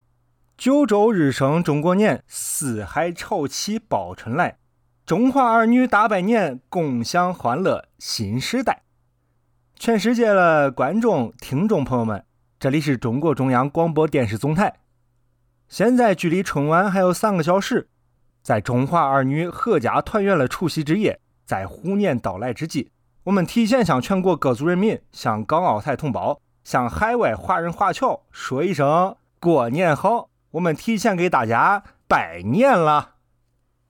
活力男声-电台主持